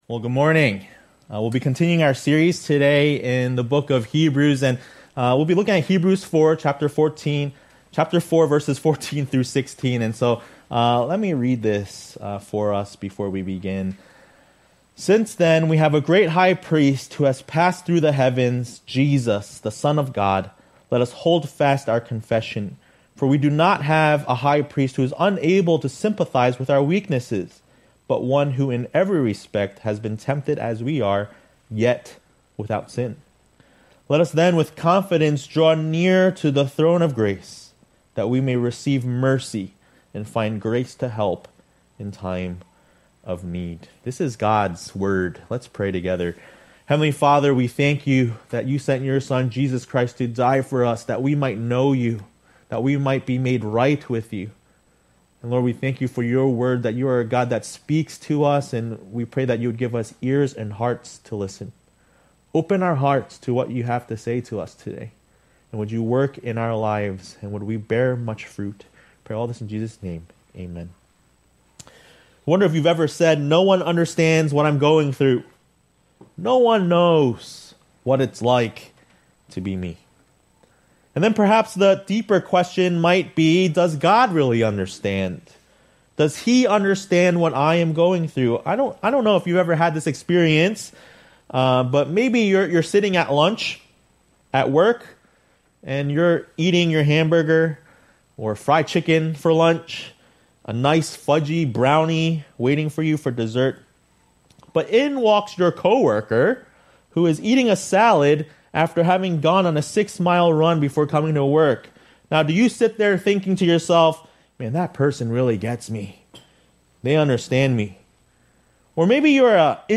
A message from the series "Hebrews."